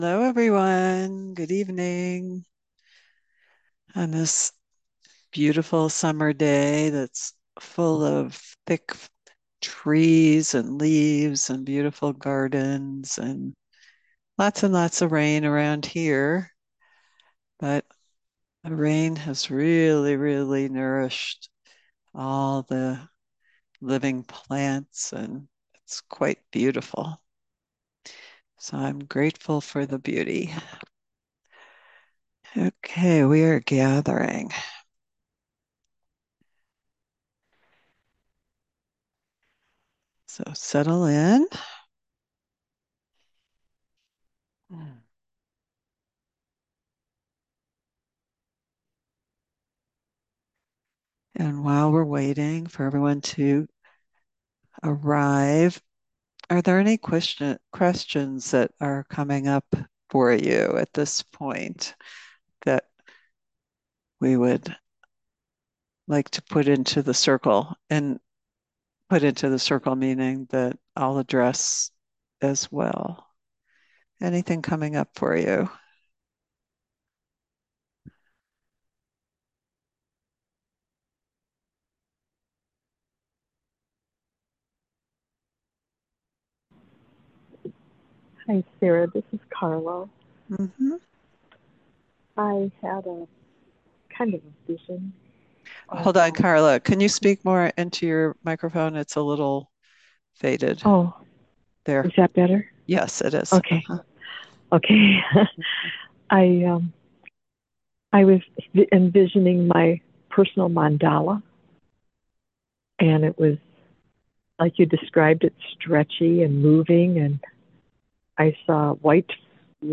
Genre: Guided Meditation.